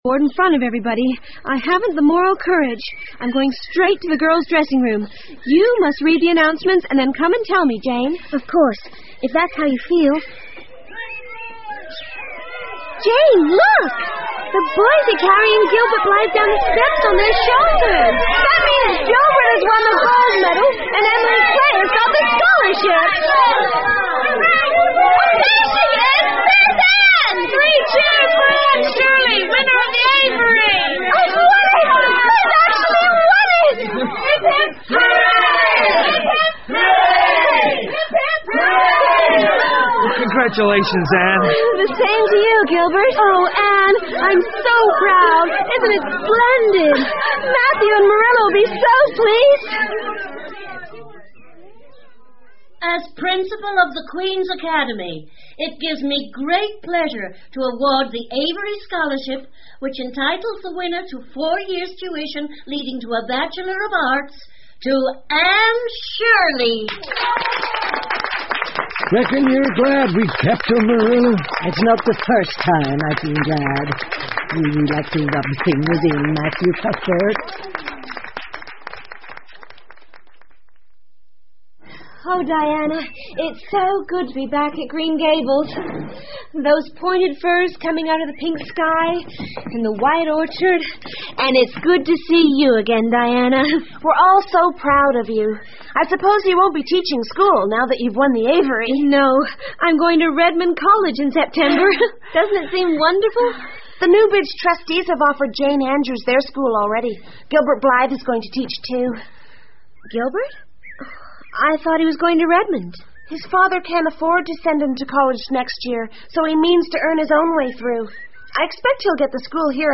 绿山墙的安妮 Anne of Green Gables 儿童广播剧 26 听力文件下载—在线英语听力室